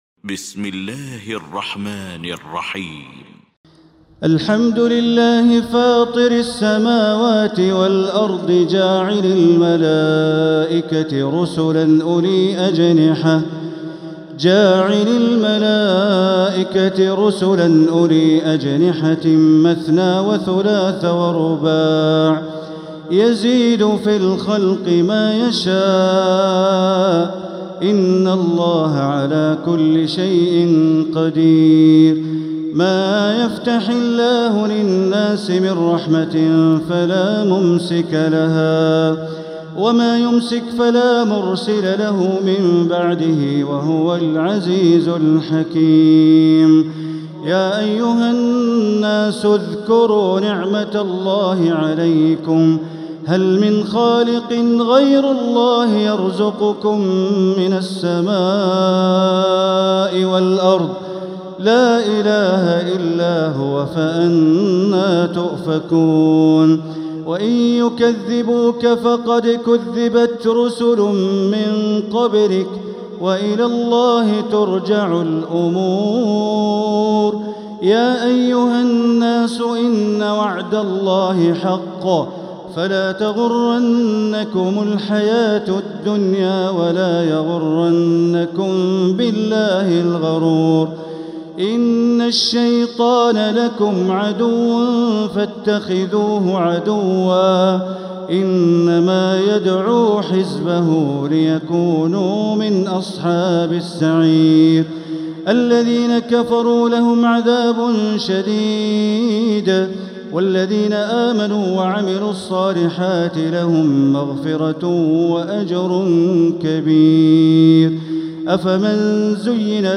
سورة فاطر Surat Fatir > مصحف تراويح الحرم المكي عام 1446هـ > المصحف - تلاوات الحرمين